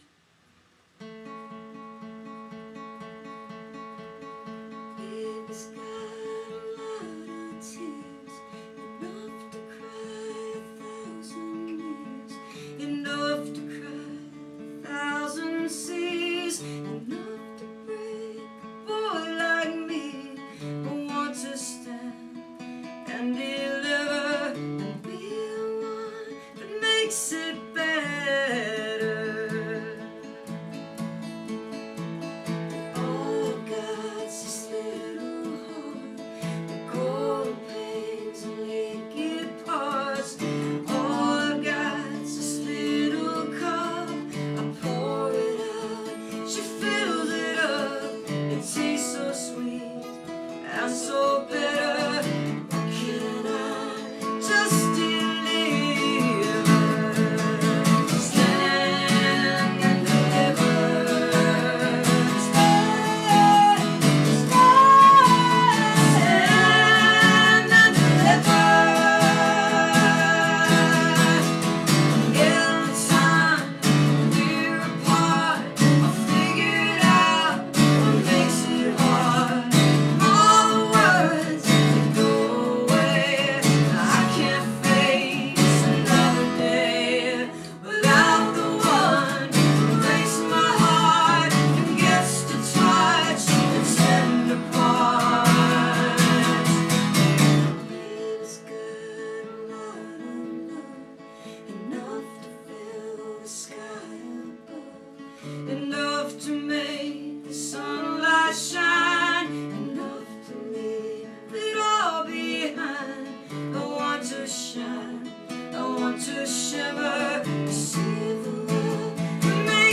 2008. all the performances are acoustic